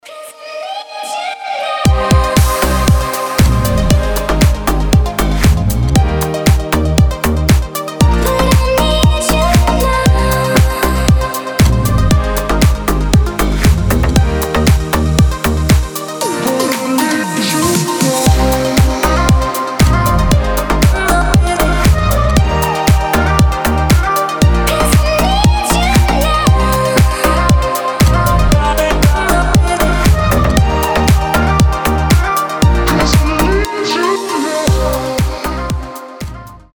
• Качество: 320, Stereo
красивый женский голос
Стиль: deep house, nu disco